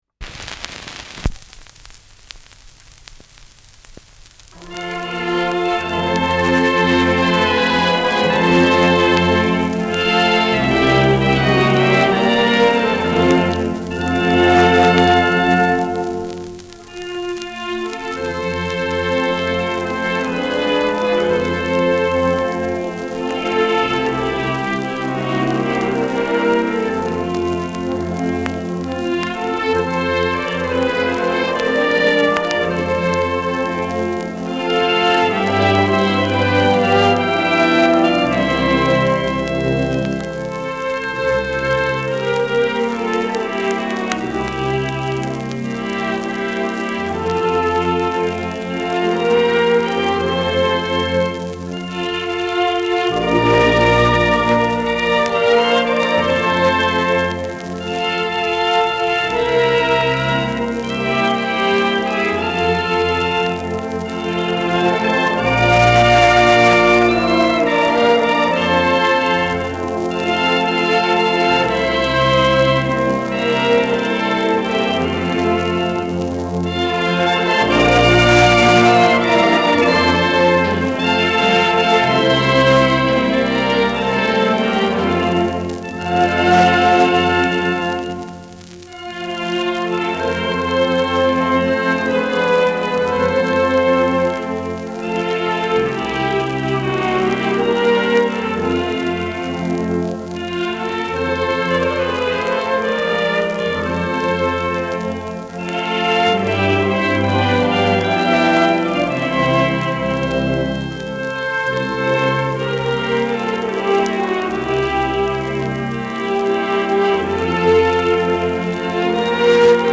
Schellackplatte
Auffällig langsam : Leiern
[Berlin] (Aufnahmeort)